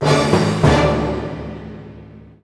flag_capture.wav